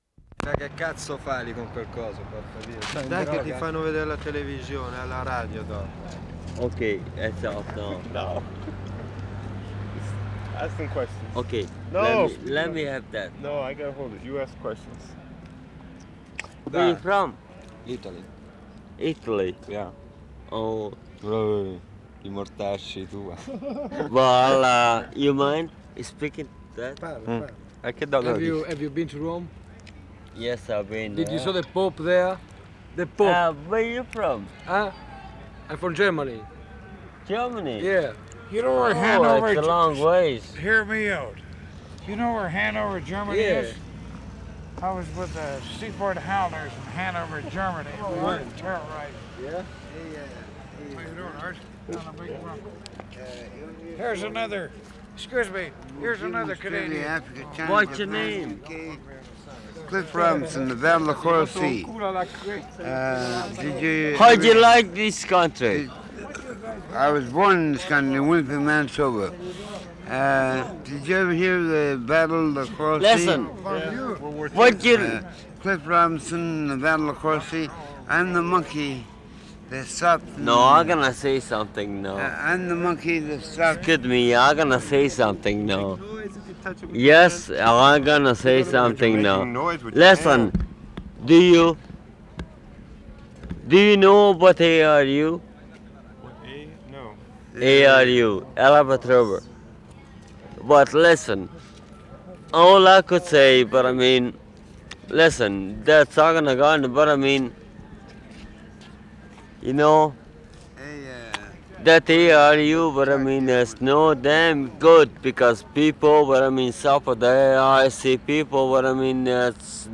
WORLD SOUNDSCAPE PROJECT TAPE LIBRARY
GASTOWN (V) June 3, 1973
STREET TALK 8'20"
1. The last, most bizarre sequence: "cross talk on all channels", background personalities emerge, pass quickly away, disintegration. The group from last reel encounter a group of Italian tourists.